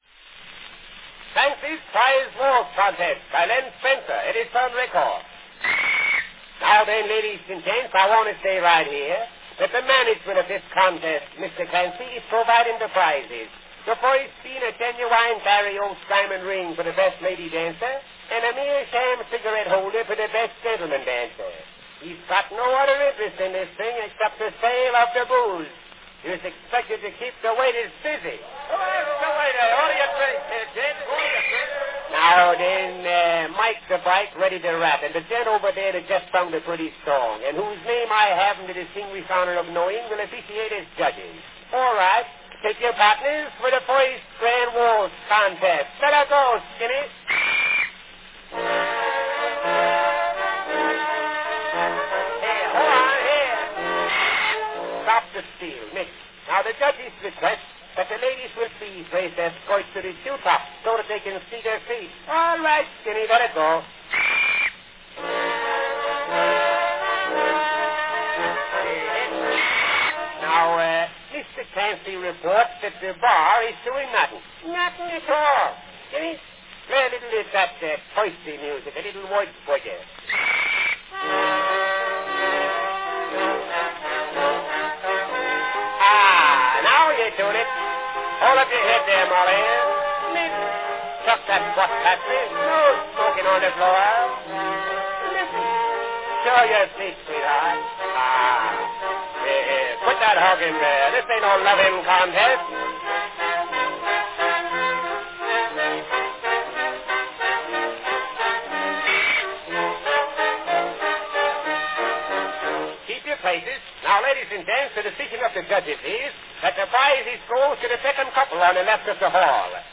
From 1904, a comedy sketch by Len Spencer, Clancy's Prize Waltz Contest.
Company Edison's National Phonograph Company
Category Comic sketch
Performed by Len Spencer
Announcement "Clancy's Prize Waltz Contest, by Len Spencer.  Edison record."
It introduces typical Bowery characters and orchestra music.
The orchestra is instructed to play that "t'irsty" music, "de Wurzburger".